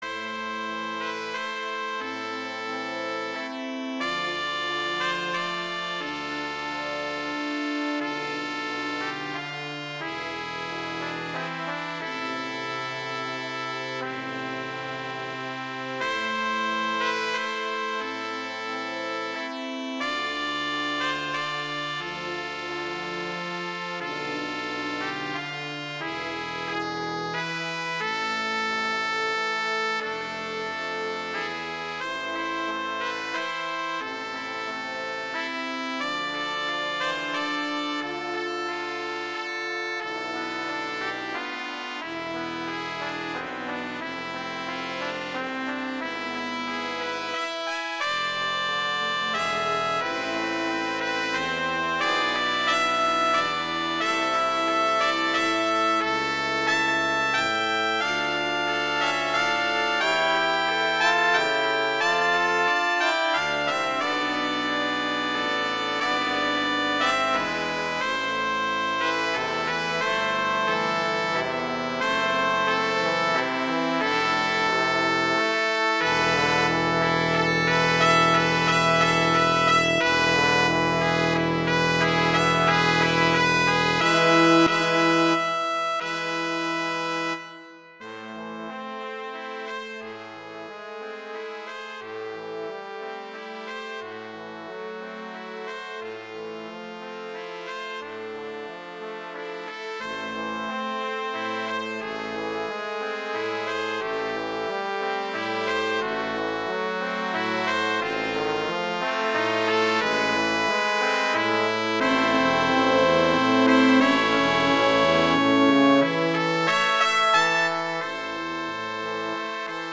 halloween, holiday
A minor
♩. = 30 BPM (real metronome 40 BPM)